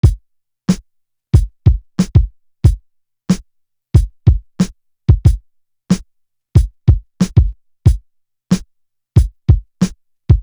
Electric Chair Drum.wav